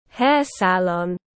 Hair salon /heər ˈsæl.ɒn/